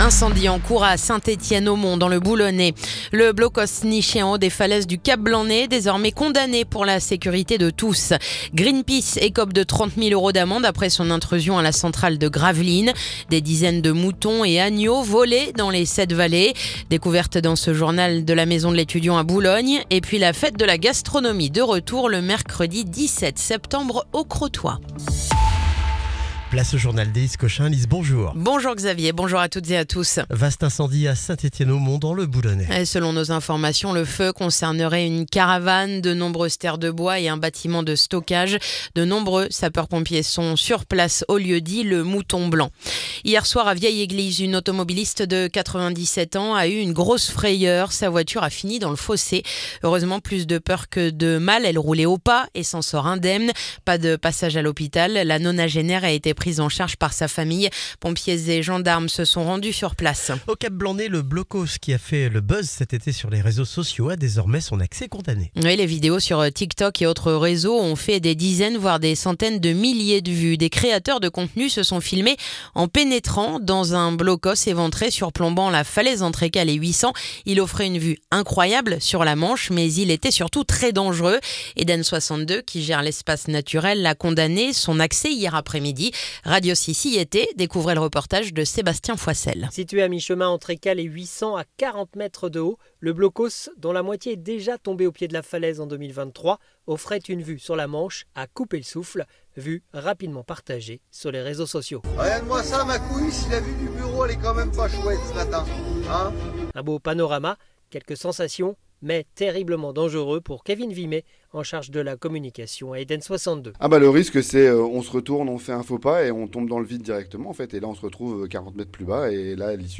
Le journal du jeudi 4 septembre